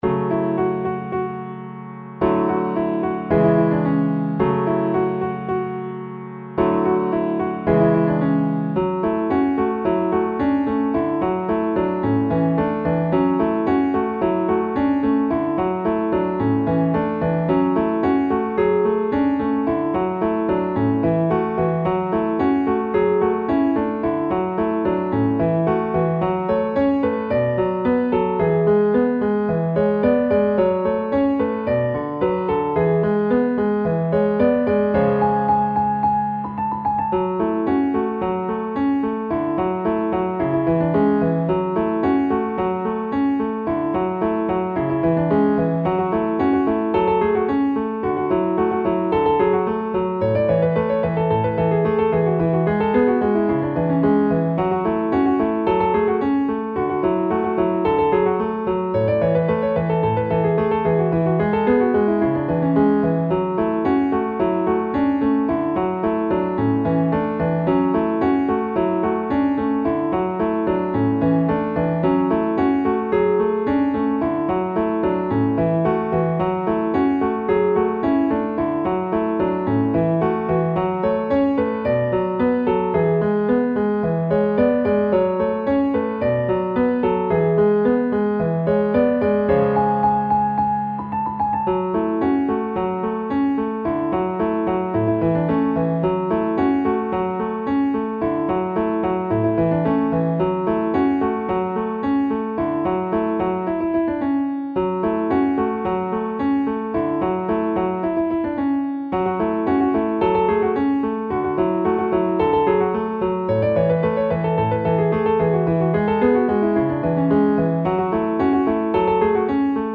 آهنگ عربی هندی